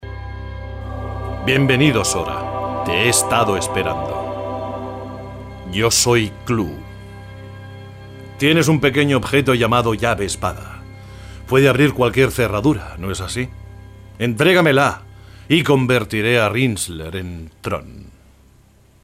Voz grave versátil, dulce y natural, y a veces fuerte.
kastilisch
Sprechprobe: eLearning (Muttersprache):
Versatile voice, sweet and warm, and sometimes strong